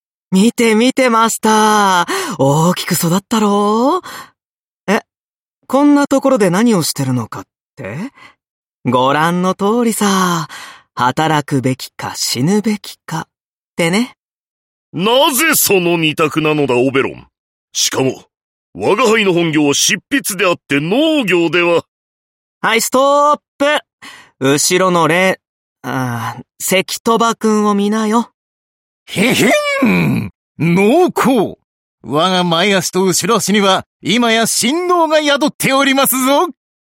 声优 丰永利行&稻田彻&绿川光